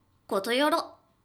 ボイス
キュート女性挨拶